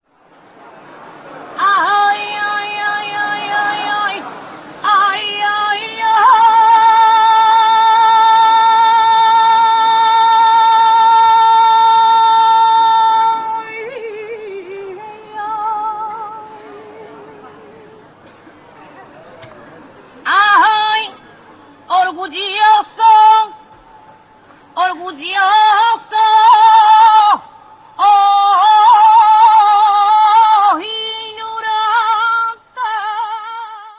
It features the tortured strains of an anonymous singer performing a saeta (arrow), to the statue of the virgin Mary and was perhaps the only song to have been included on this disc in its entirety.  Saetas are performed periodically during the procession, to give the statue bearers a rest, and they are as brief as they are agonising.